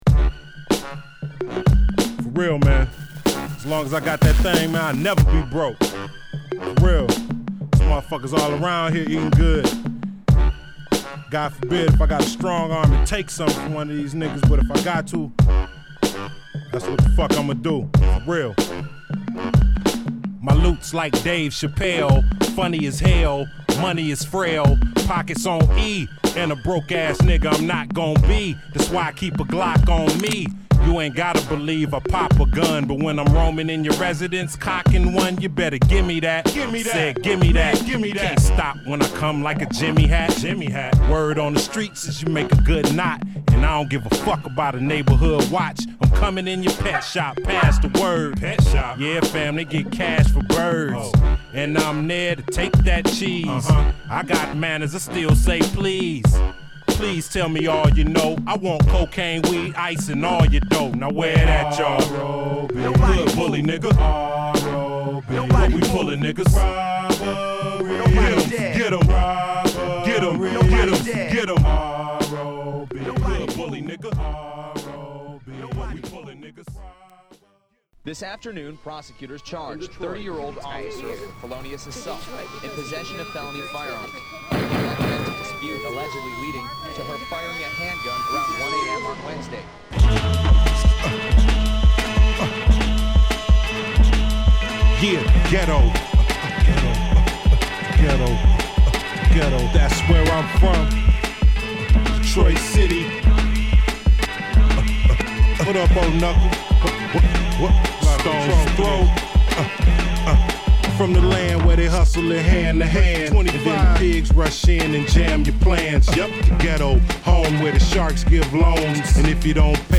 独特のダミ声で、ハードコアな作品を量産するMC